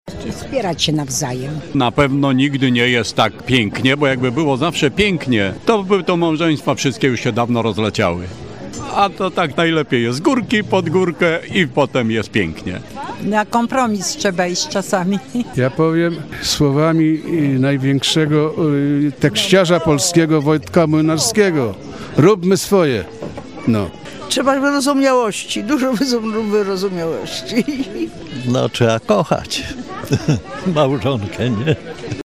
Dziś w Stargardzkim Centrum Kultury odbyła się wyjątkowa uroczystość – Złote Gody.